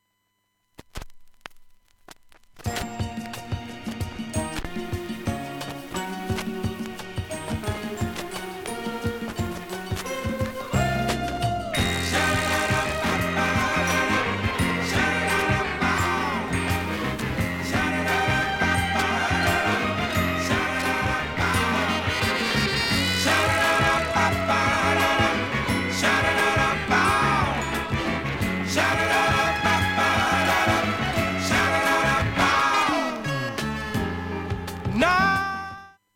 A-1出だしに5秒の箇所で針飛び修正しましたが、針飛びします。
その部分２箇所にこすれがあります。ノイズ数回出ます。